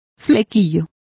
Complete with pronunciation of the translation of fringes.